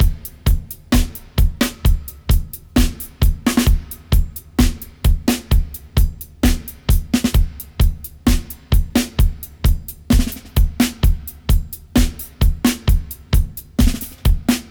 129-FX-03.wav